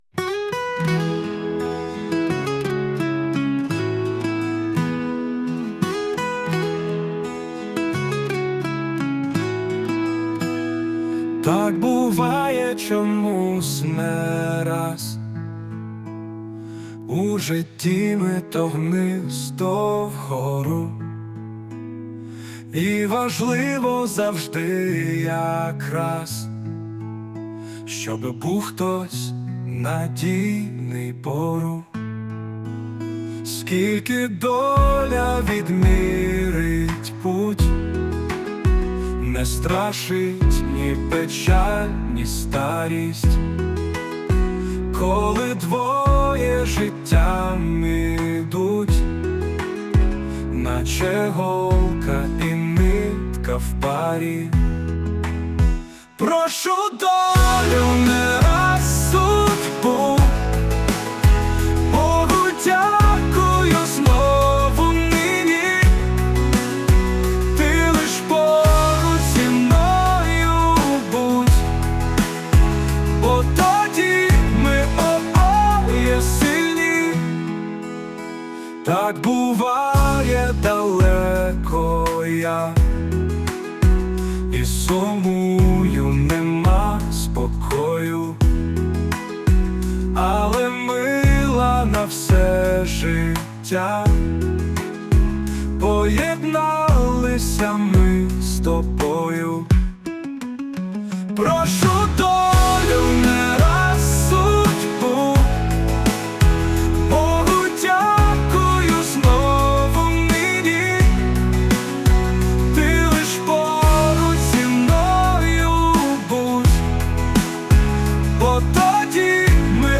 музичний супровід :ші суно